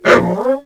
I wanted Volkov & Chitzkoi voices, but wasn't sure what to use, so i decided to take the C&C Remastered RA1 voice lines and triplex them, with some adjustments, to make them cybornetic sounding.